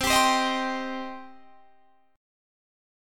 Cm Chord
Listen to Cm strummed